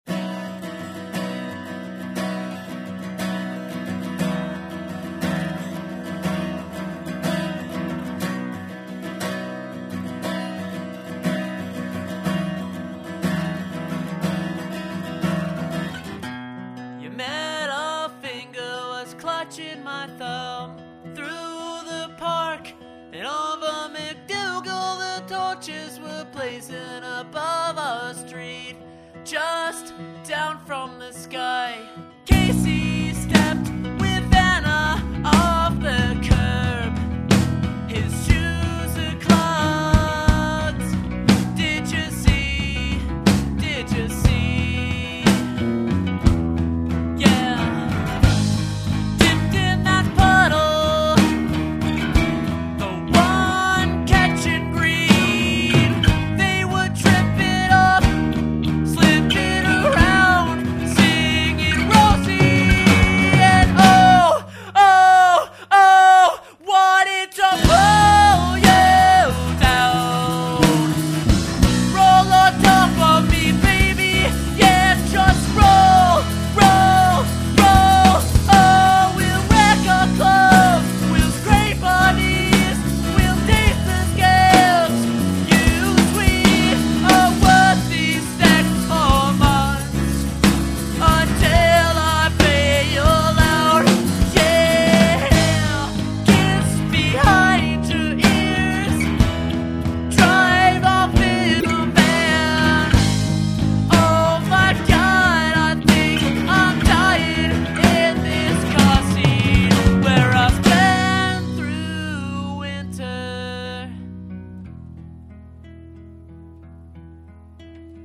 i love their acoustic music.